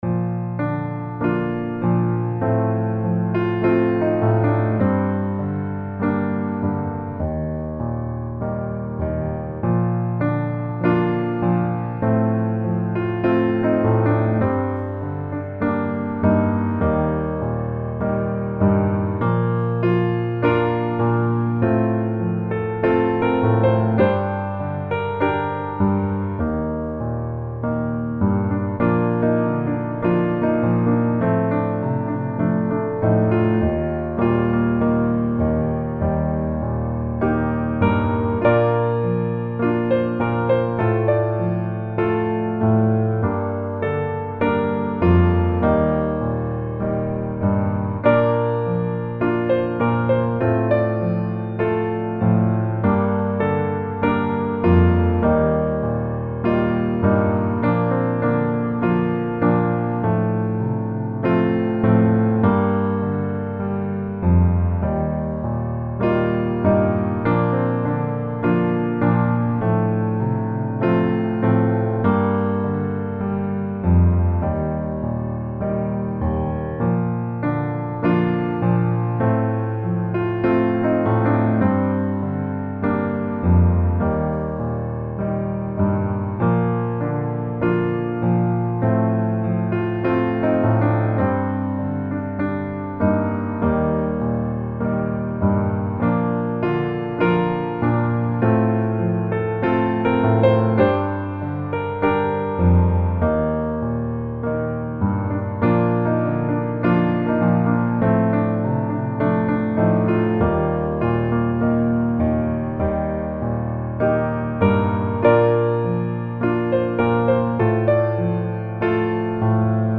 Bb Majeur